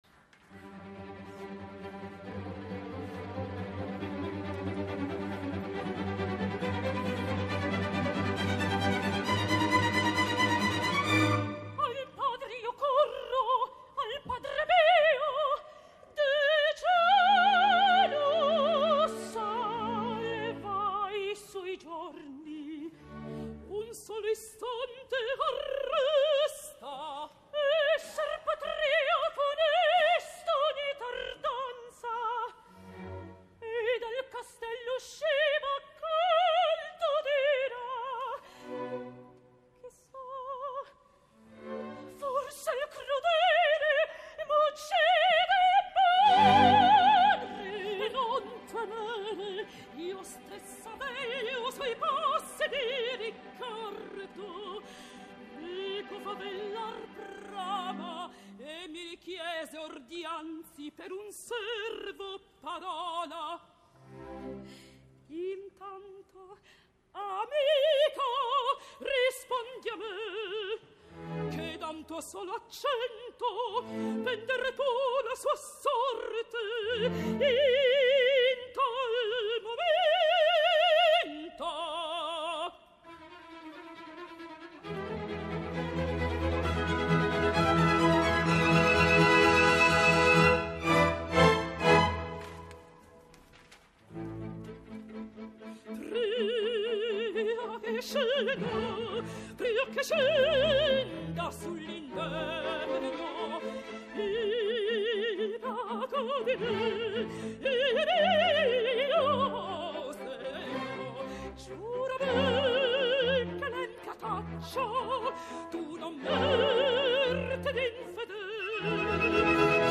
Ella és de tots quatre, la que més m’ha agradat, tot i que alguns aguts li sonen forçats, i la zona greu sigui més aviat sorda, cosa que denota avui per avui, que encara li manca una mica per ser una verdiana autèntica, o si més no plena, continua ostentant el títol de gran promesa.
El mestre Frizza, fa sonar l’orquestra de la Scala de manera vulgar, pobre, poc distingida.
Aquest número no estava inclòs a l’estrena i es cantat després de la segona ària de Cunizia a l’inici del segon acte, es tracta de “Al padre io corro” que canten Leonora i Cunizia, és a dir, Maria Agresta i Sonia Ganassi.
Orchestra e Coro del Teatro alla Scala di Milano
Teatro alla Scala di Milano, 17 d’abril de 2013